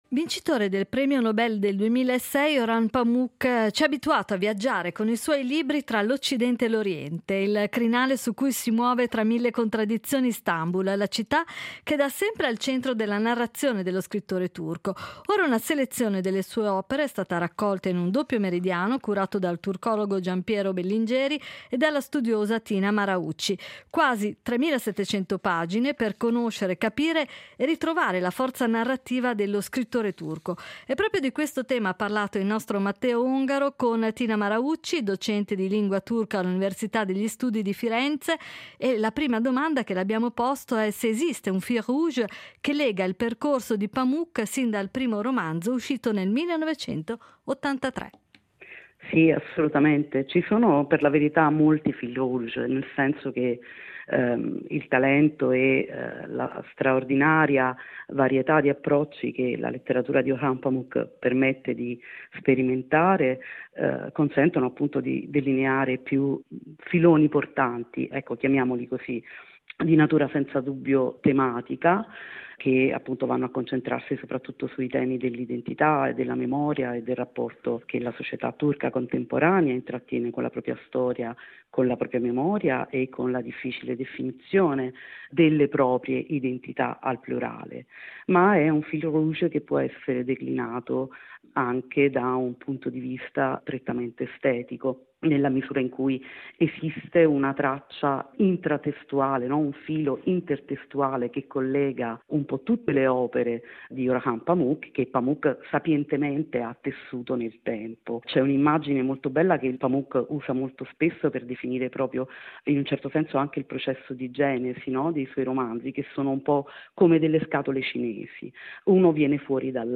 Ad Alphaville abbiamo avuto ospite